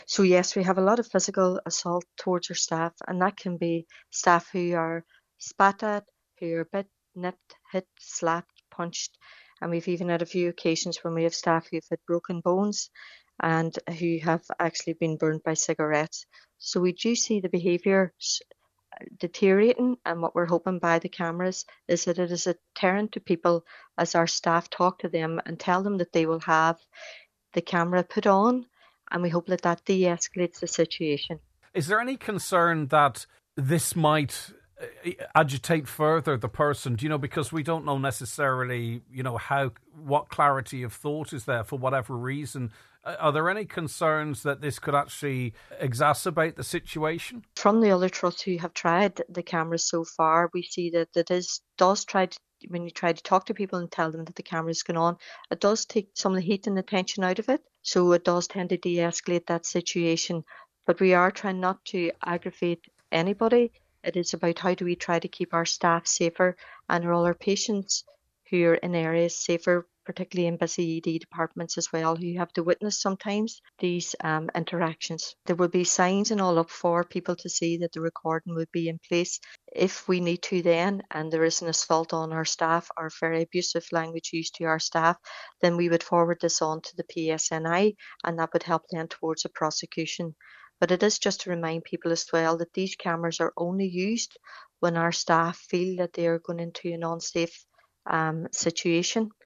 on this morning’s Nine til Noon Show